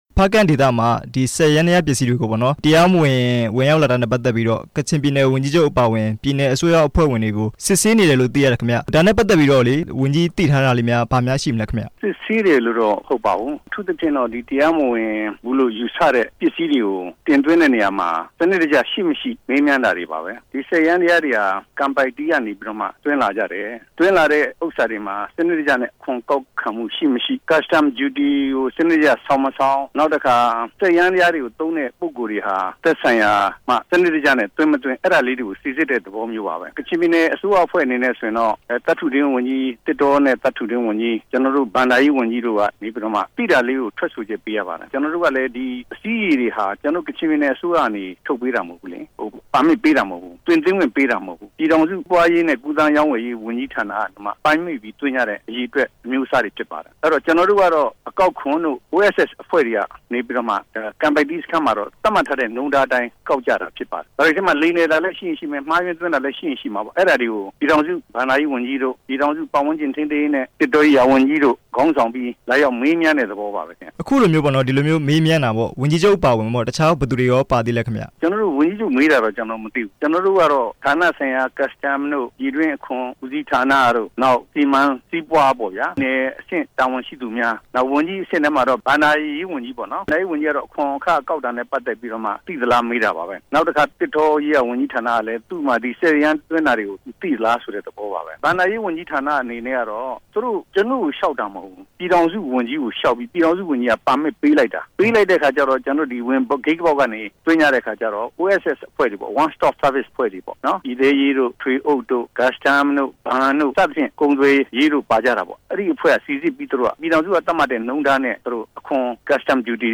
ဦးညွန့်အောင်နဲ့ မေးမြန်းချက်